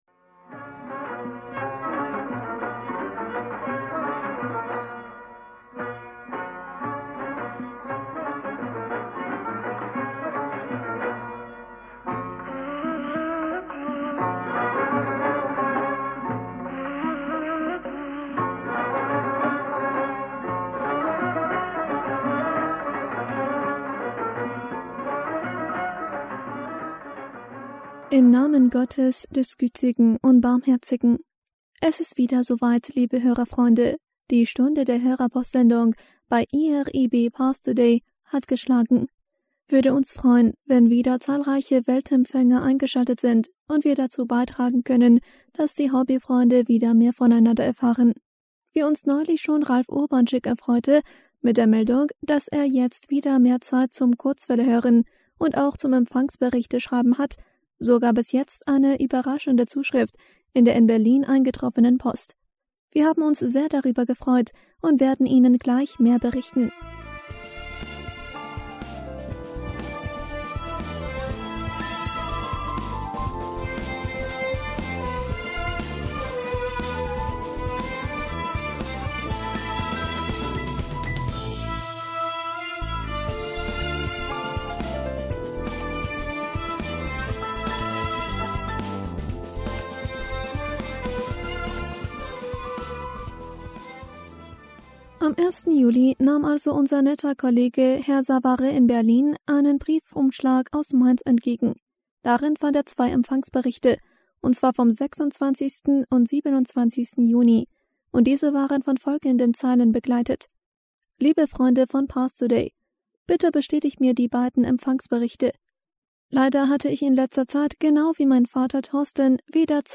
Hörerpostsendung am 12. Juli 2020